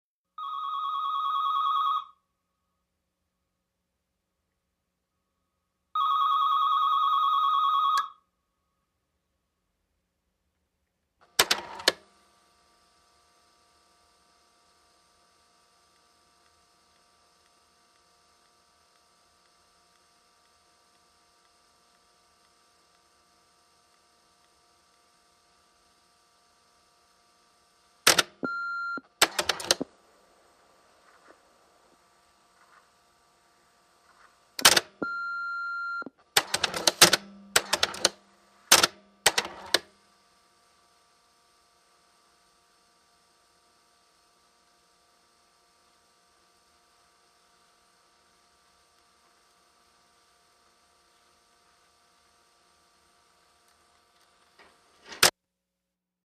Answering machine, cassette type, record, beeps, rewind, stop, beeps